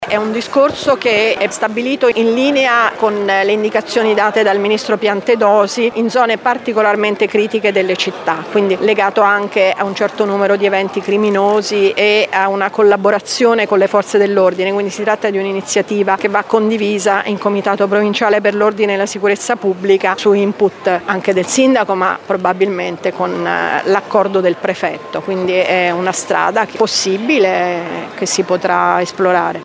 Ne aveva parlato di recente l’assessore comunale Alessandra Camporota: